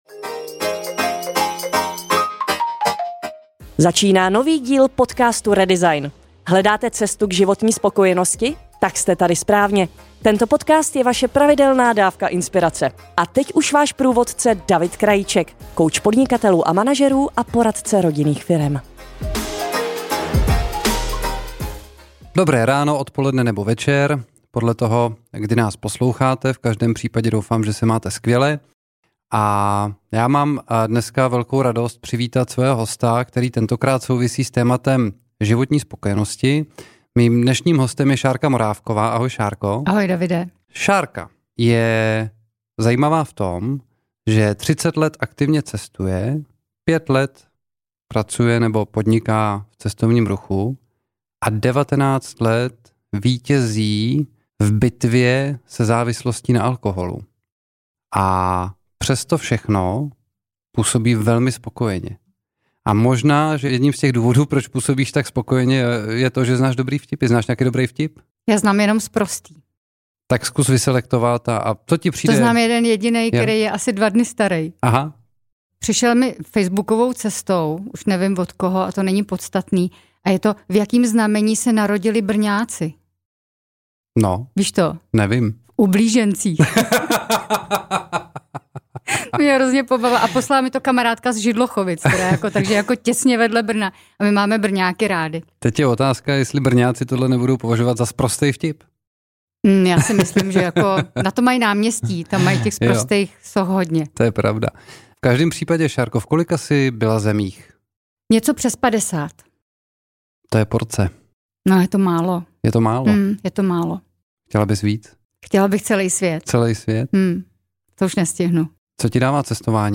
Poslechněte si rozhovor na téma životní spokojenosti.